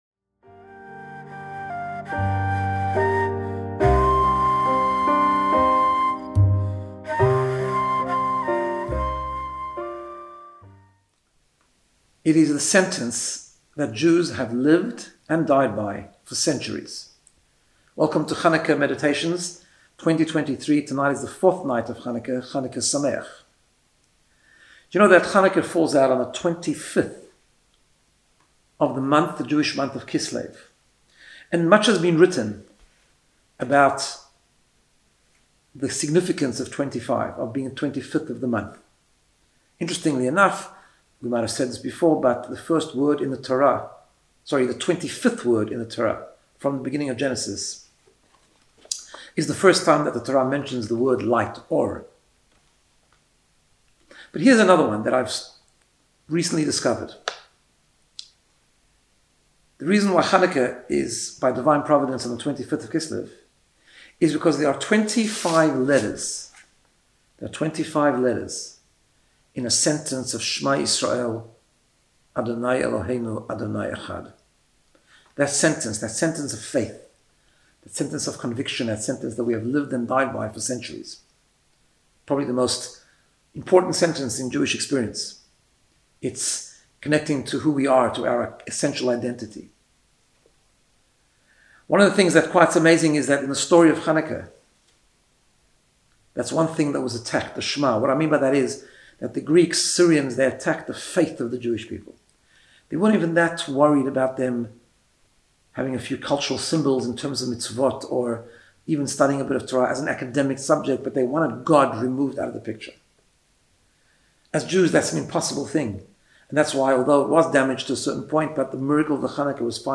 Guided Meditation to get you in the space of Personal Growth and Wellbeing.